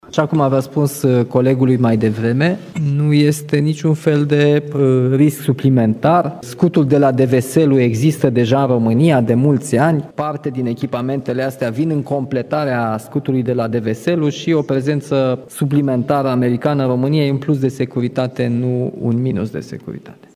Președintele României, Nicușor Dan, garantează că țara noastră nu se află sub niciun fel de risc, după ce Parlamentul a aprobat cererea Statelor Unite de a găzdui noi echipamente și militari americani. Într-o conferință comună, alături de președintele Ucrainei, Nicușor Dan a declarat că scopul noilor forțe americane este garantarea securității.